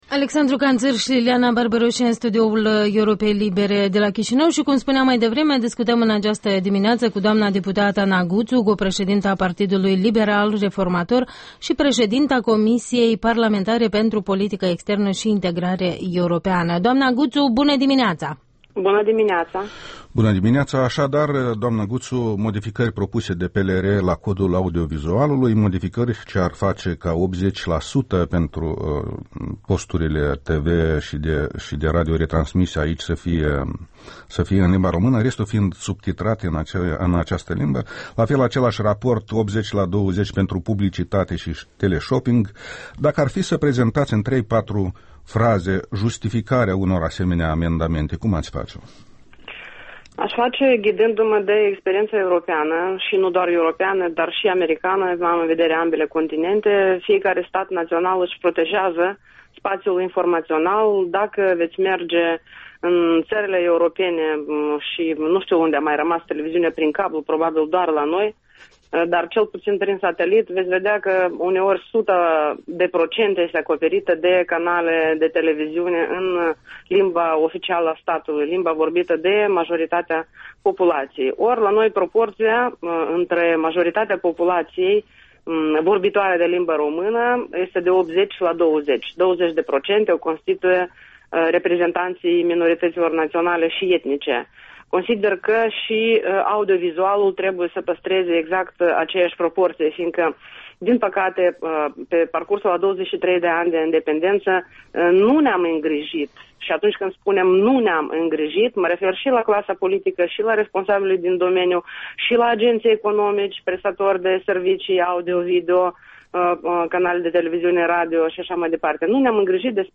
Interviul dimineții cu Ana Guțu, preşedinta Comisiei parlamentare pentru Politică Externă şi Integrare Europeană.